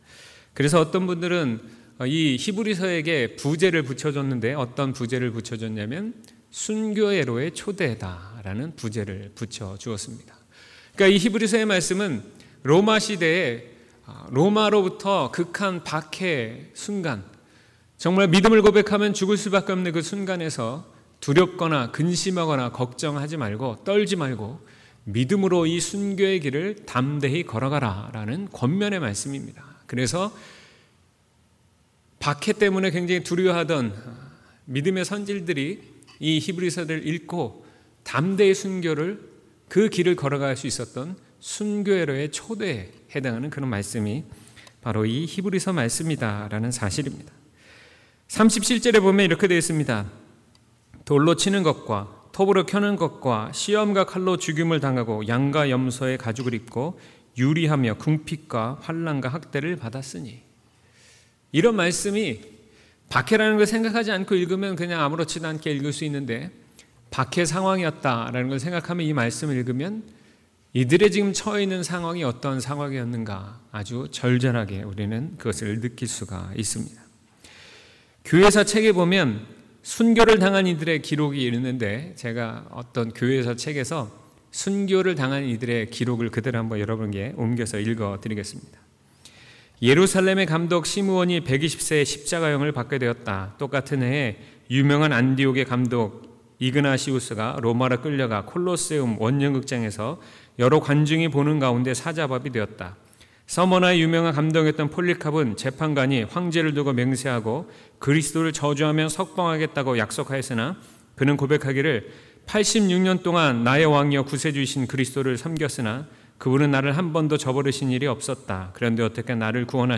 2019년 5월 26일 주일 설교/세상이 감당하지 못하는 사람/히11:33-40